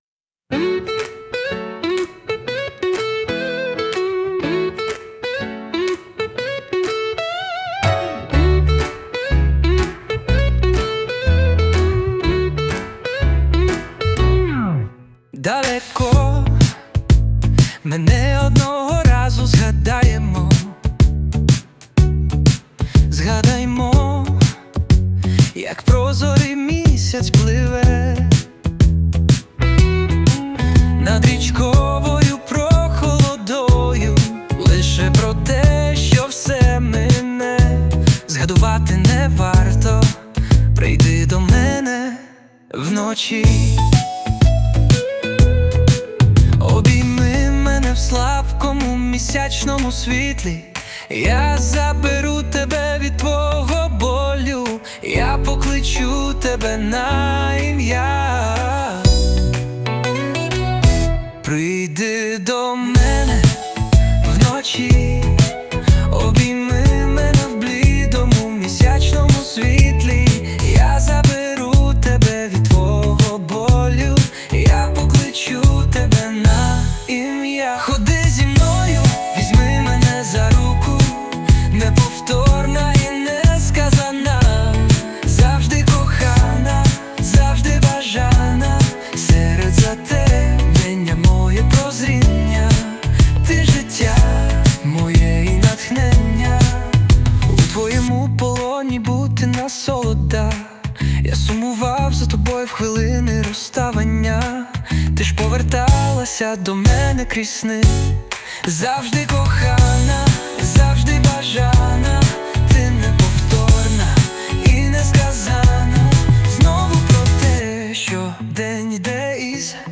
СТИЛЬОВІ ЖАНРИ: Ліричний
Ніжно, трепетно і віддано.